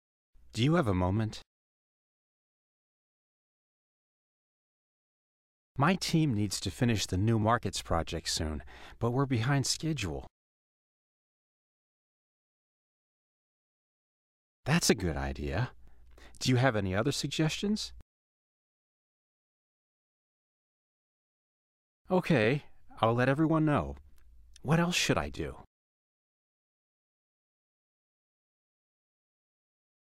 Listen to this conversation about the needs of an office project and check the words and expressions you know.
Now listen again and do yourself the part of the man who offers the solution as many times as necessary for a good performance.